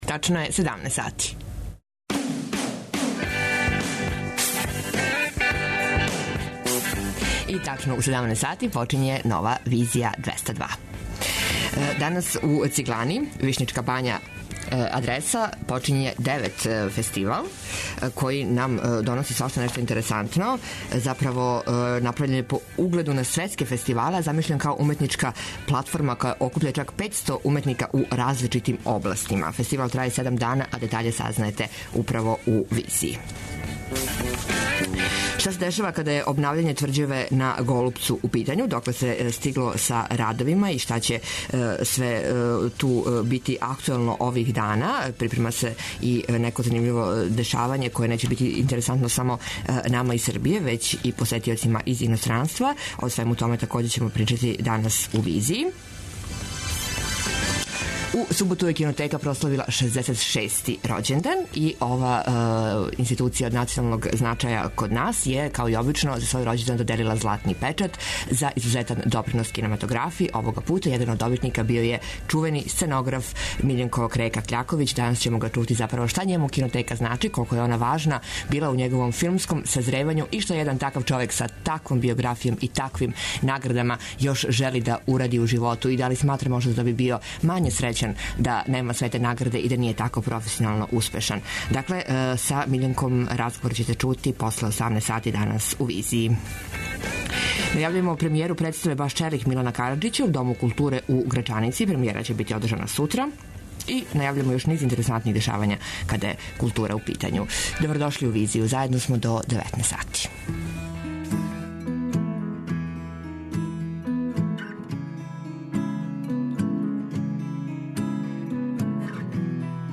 Социо-културолошки магазин, који прати савремене друштвене феномене.